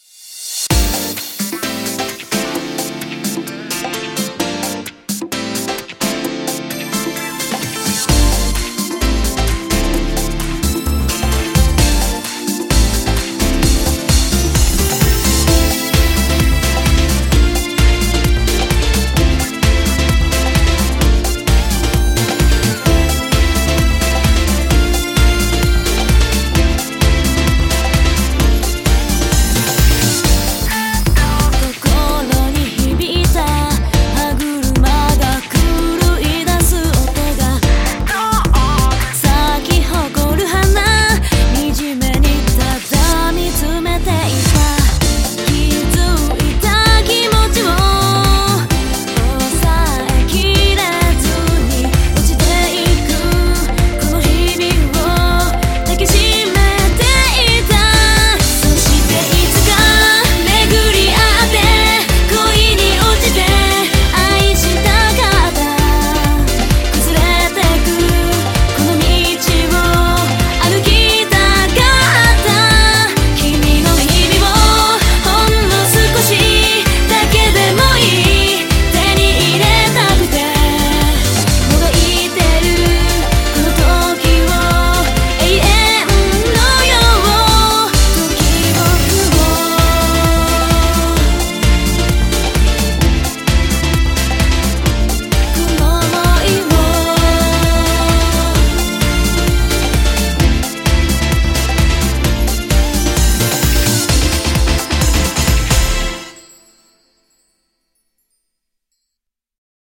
BPM130